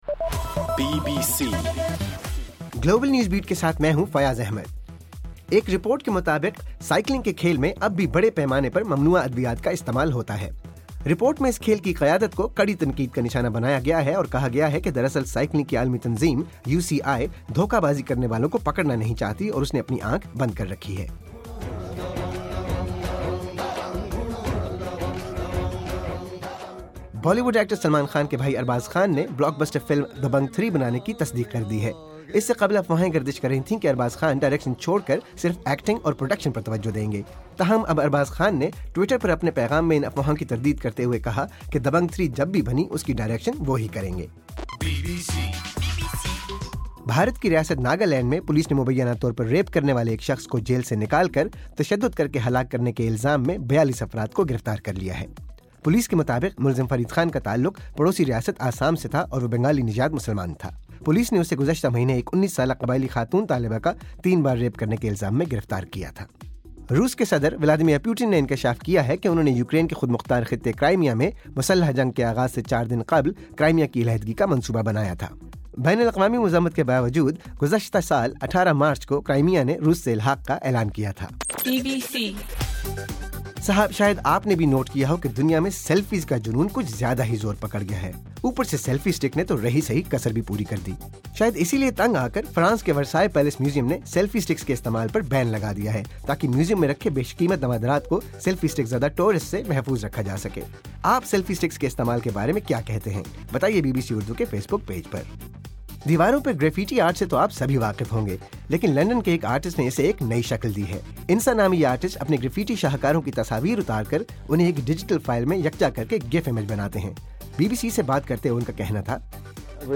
مارچ 09: رات 11 بجے کا گلوبل نیوز بیٹ بُلیٹن